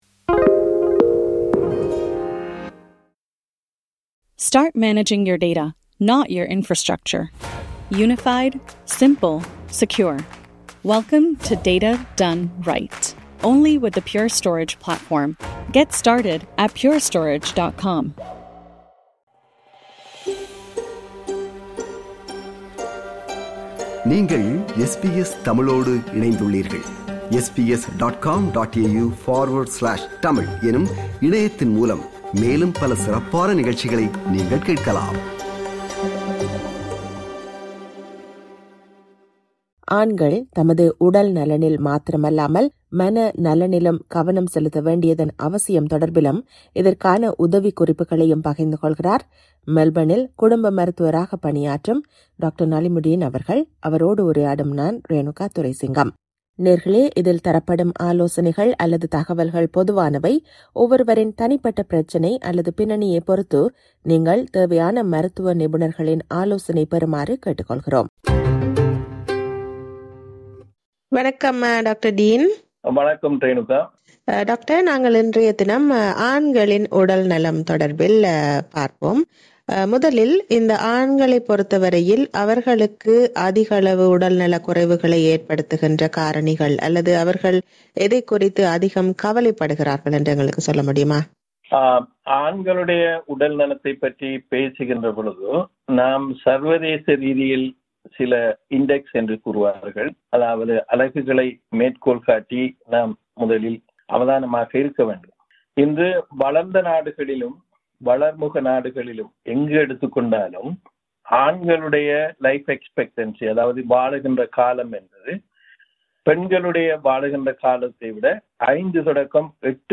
அவரோடு உரையாடுகிறார்